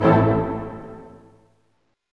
HIT ORCHM0FR.wav